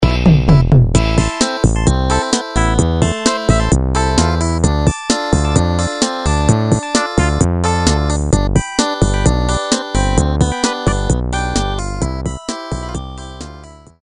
Siemens полифония. Шансон